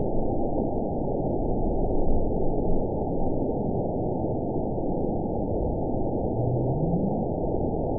event 920018 date 02/07/24 time 00:22:16 GMT (1 year, 4 months ago) score 9.60 location TSS-AB09 detected by nrw target species NRW annotations +NRW Spectrogram: Frequency (kHz) vs. Time (s) audio not available .wav